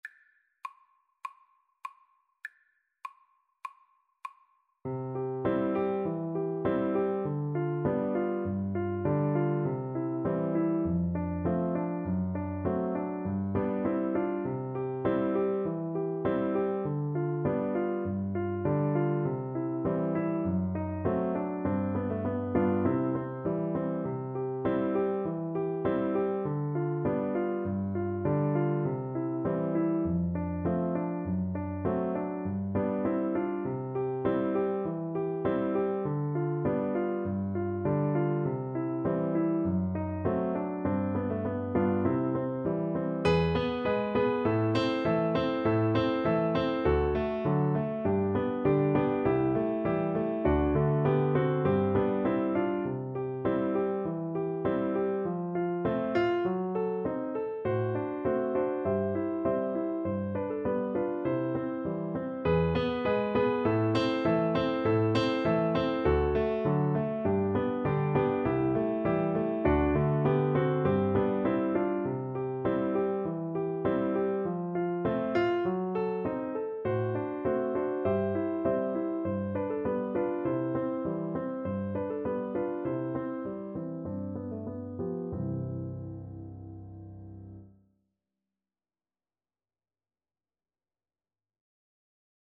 Classical (View more Classical Flute Music)